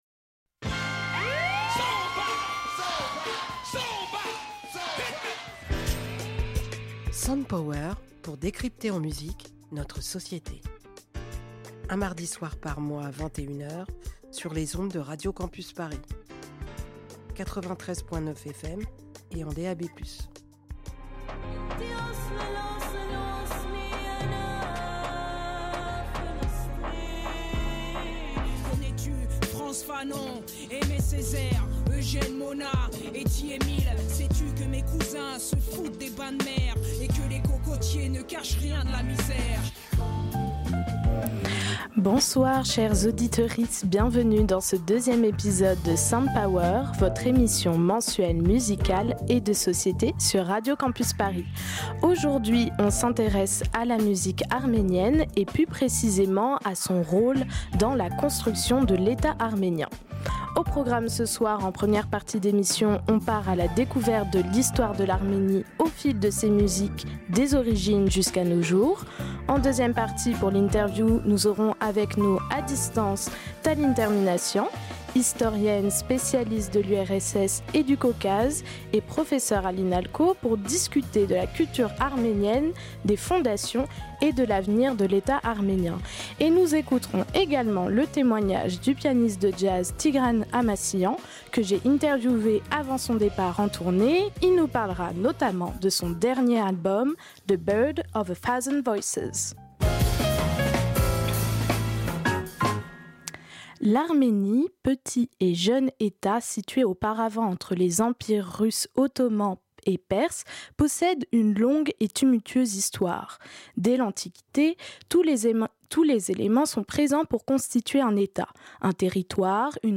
Vous traverserez les siècles avec des chants folkloriques, des musiques classiques, jazz et pop qui rendent hommage et racontent la culture arménienne.
Entretien